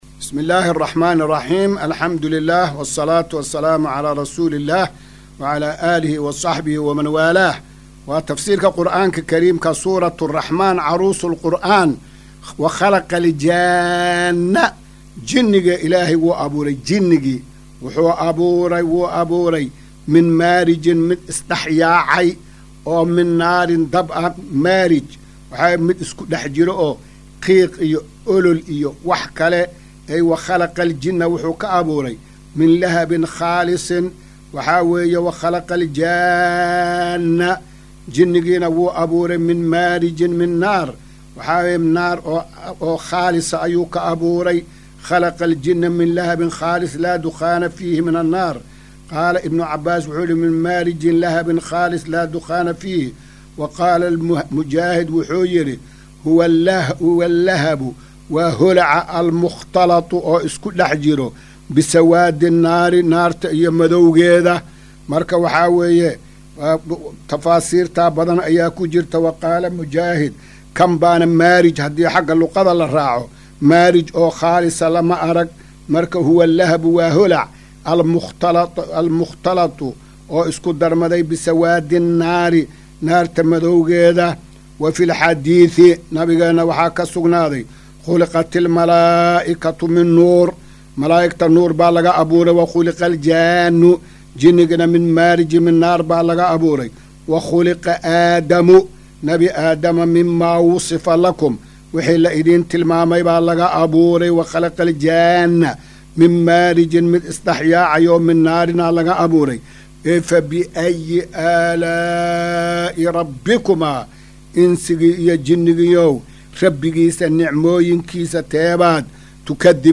Maqal:- Casharka Tafsiirka Qur’aanka Idaacadda Himilo “Darsiga 253aad”